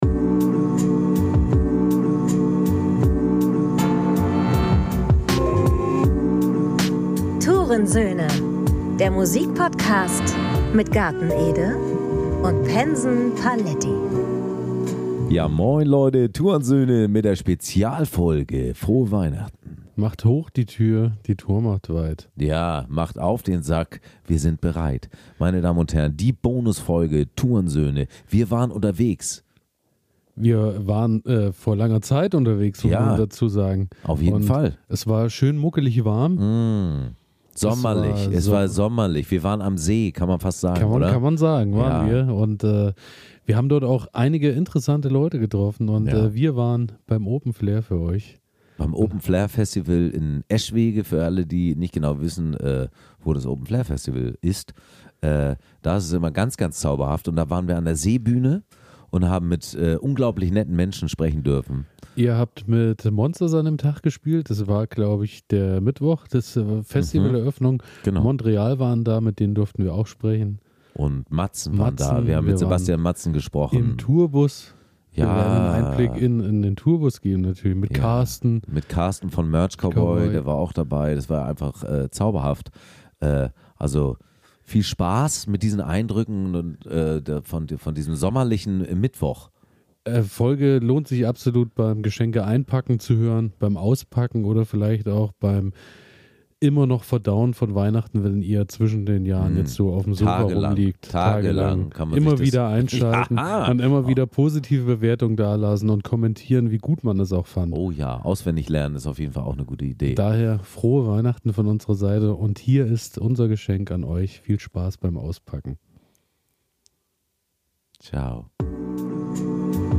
Hier unser Geschenk an Dich: die exclusive Bonusfolge vom diesjährigen Open Flair!